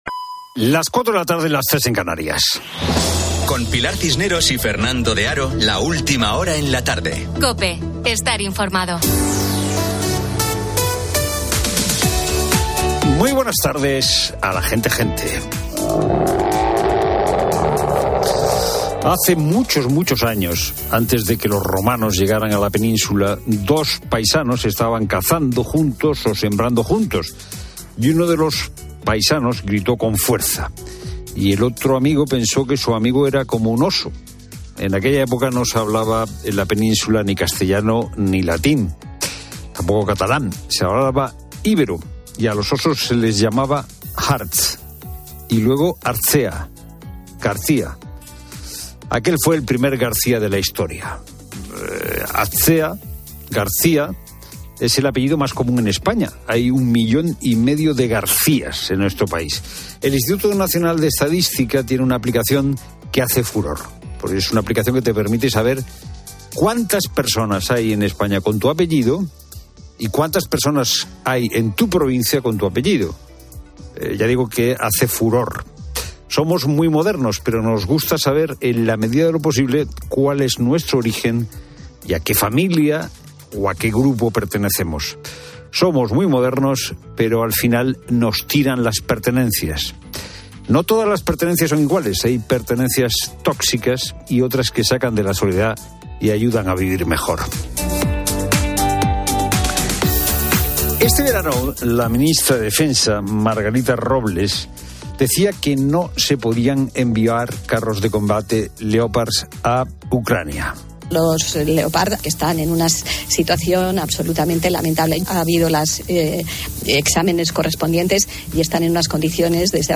¿La creatividad es cosa de cerebros jóvenes?; Y tertulia política